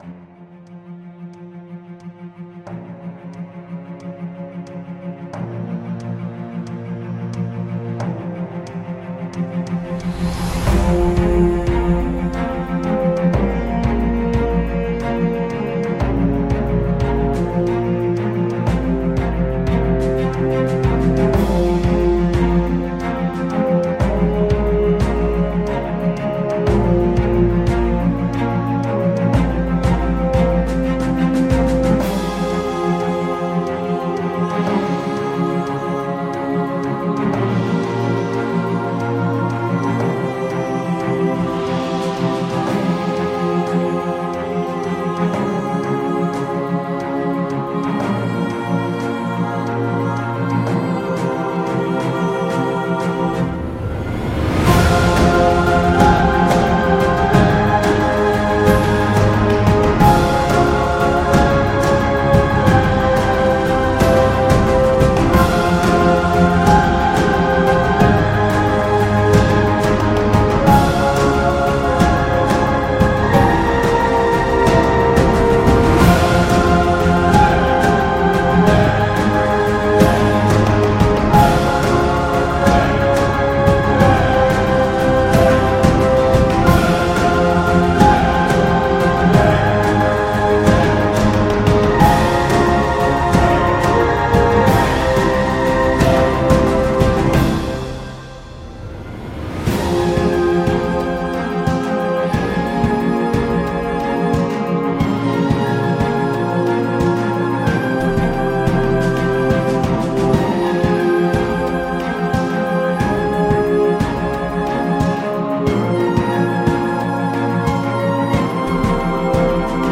3 (Epic)